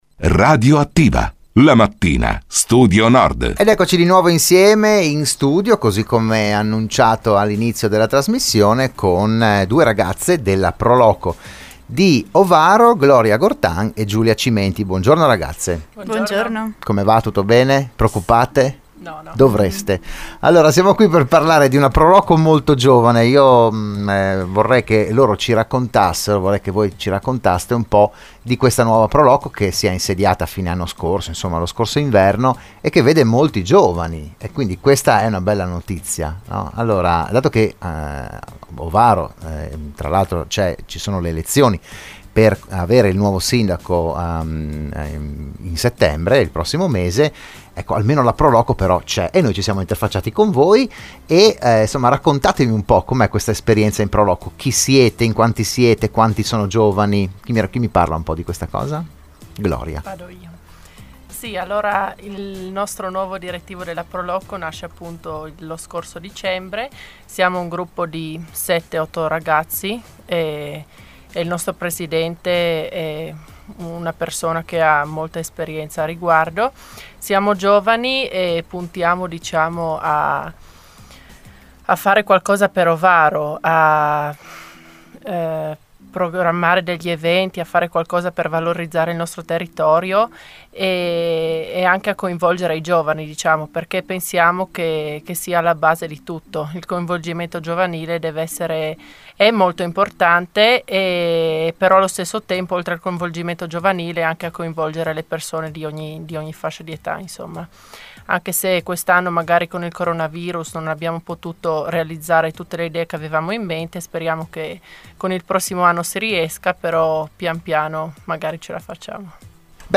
Dell’appuntamento si è parlato oggi a “RadioAttiva“